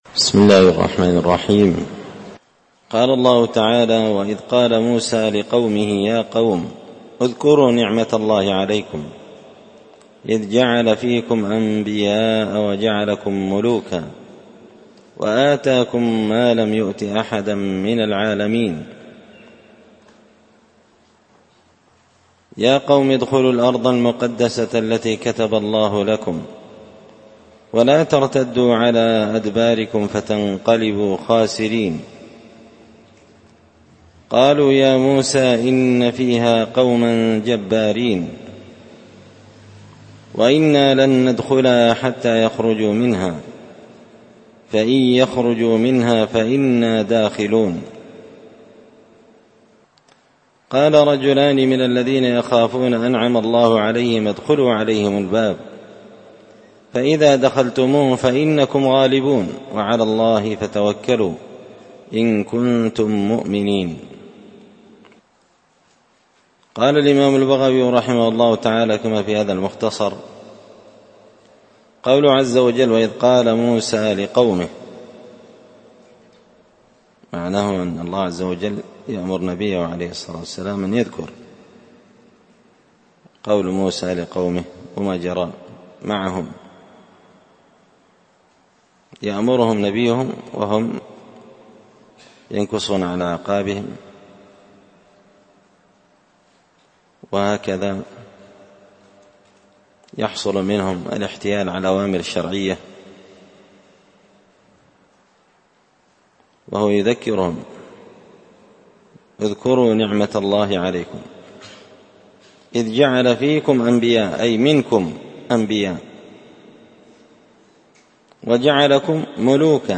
دار الحديث بمسجد الفرقان ـ قشن ـ المهرة ـ اليمن